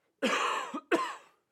Human, Cough, Male 03 SND12821 1.wav